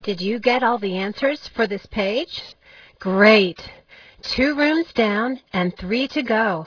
Sound: Listen to Klio (a Muse!) tell you the instructions (07").